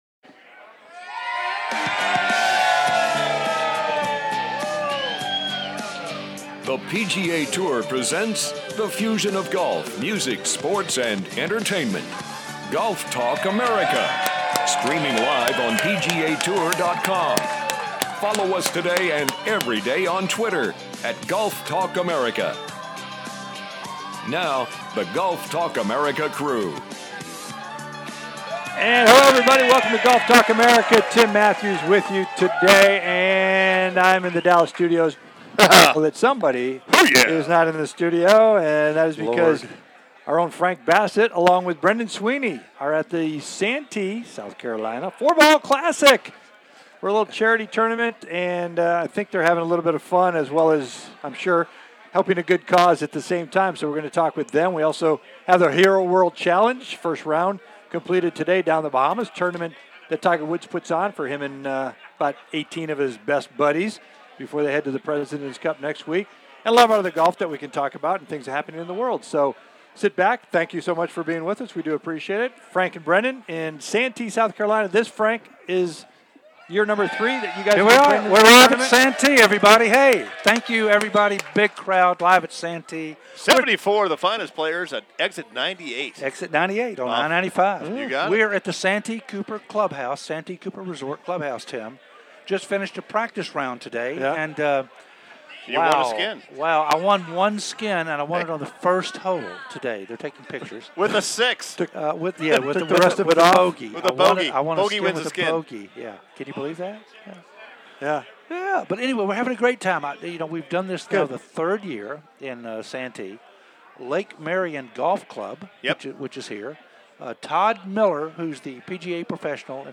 "LIVE" From The Santee 4 Ball Classic
The Santee 4 Ball Classic at Santee Cooper Resort & Lake Marion Golf Club